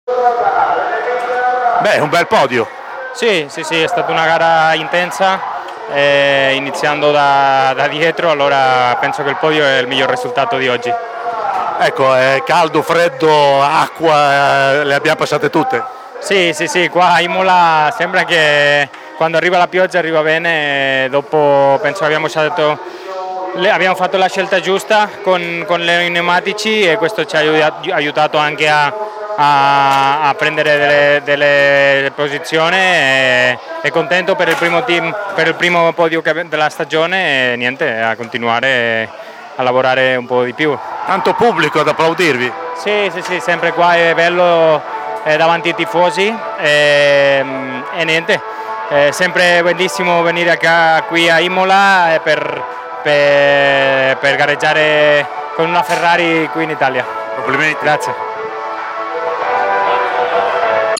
Miguel Molina, vincitore l’anno scorso con la Ferraro 499 della 24 ore di Le Mans